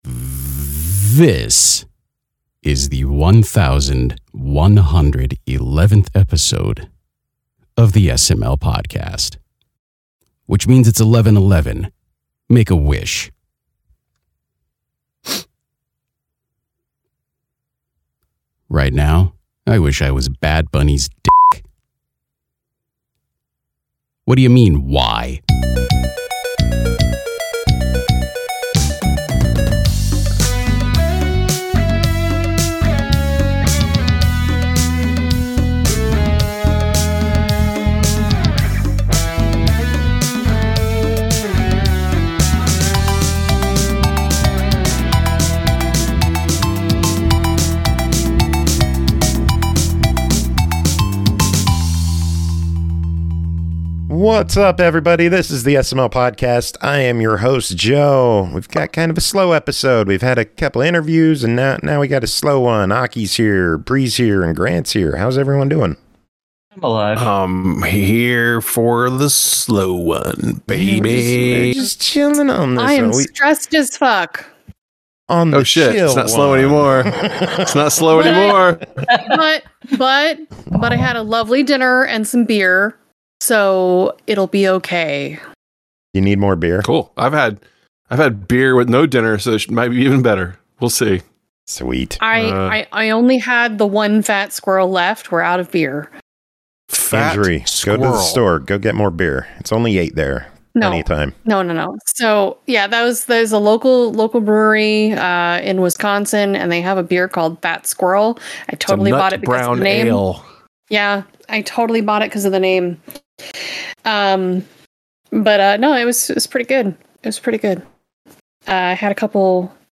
0:00 – Intro/Banter